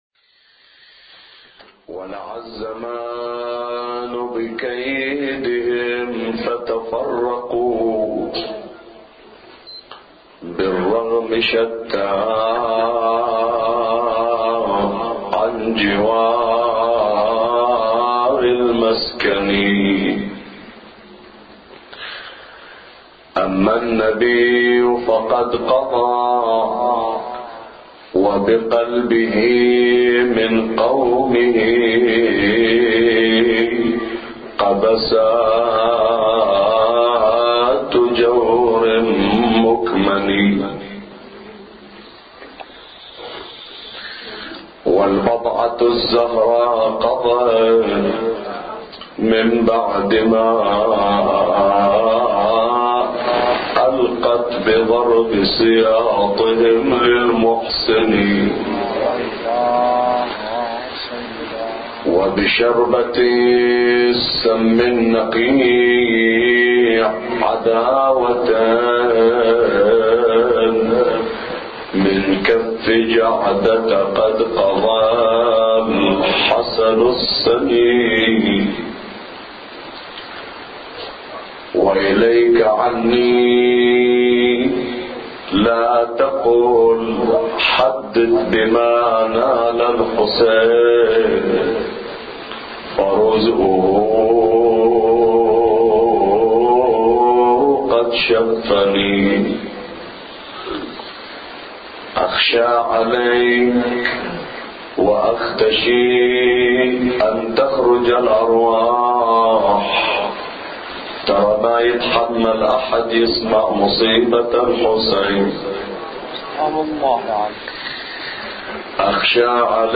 أبيات حسينية – ليلة الثاني من شهر محرم